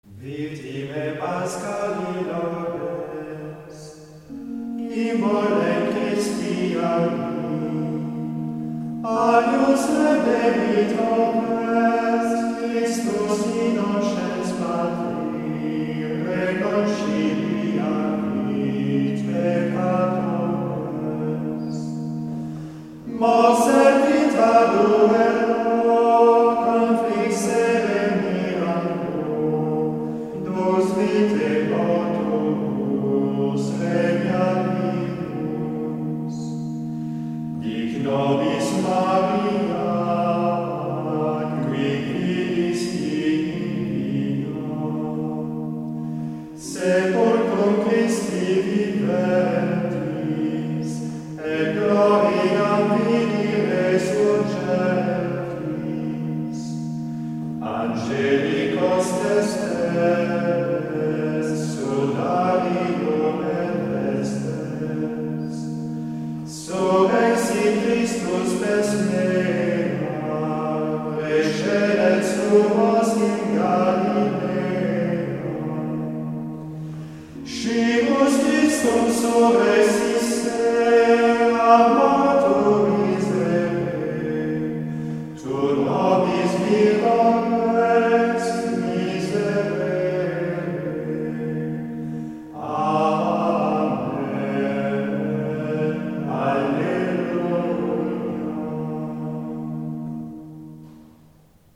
Se entiende comúnmente por canto gregoriano un género de música a una sola voz, de tonalidad diatónica en general y ritmo libre.
163-victimae-secuencia.mp3